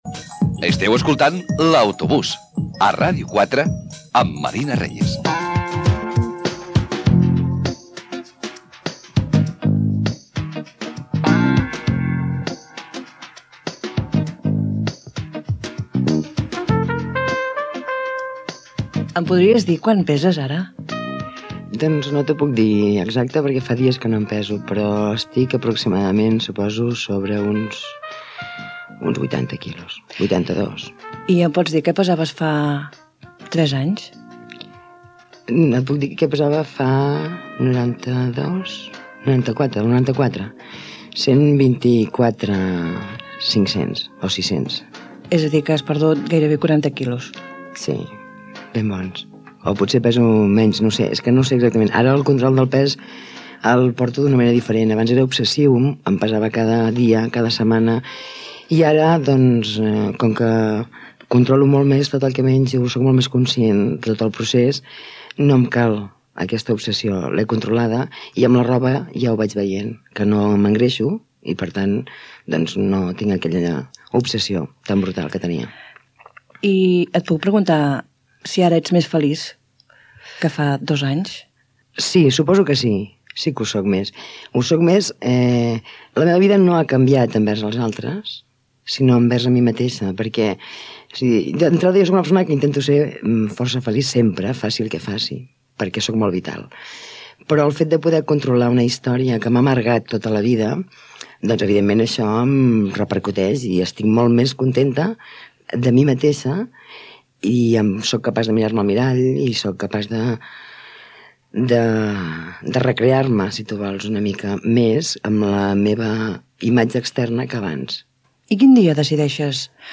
Indicatiu del programa, el sobrepès. L'entrevistada explica com ha estat la reducció de pes després d'una intervenció quirúrgica a l'hospital de Can Ruti de Badalona
Entreteniment